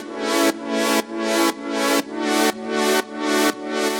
Index of /musicradar/french-house-chillout-samples/120bpm
FHC_Pad A_120-A.wav